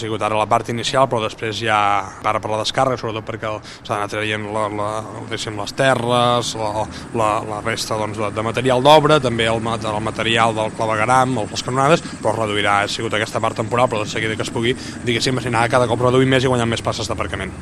“De seguida que es pugui, es recuperaran”, ha afirmat l’alcalde Marc Buch en una entrevista a peu de carrer que ha concedit al magazine a l’FM i + per dotar detalls del projecte.